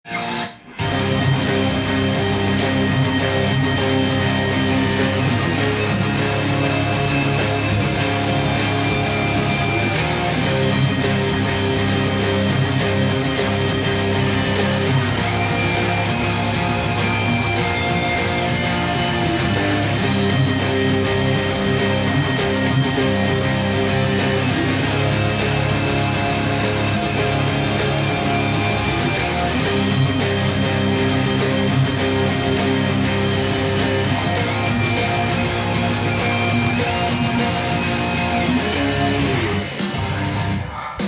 これはエレキギターの練習に丁度良さそうな曲です！
音源に合わせて譜面通り弾いてみました
ロックで多用されるブリッジミュートと
パワーコードそして、sus4系パワーコードで構成されています。
近未来的なギターサウンドがするのでこのコードを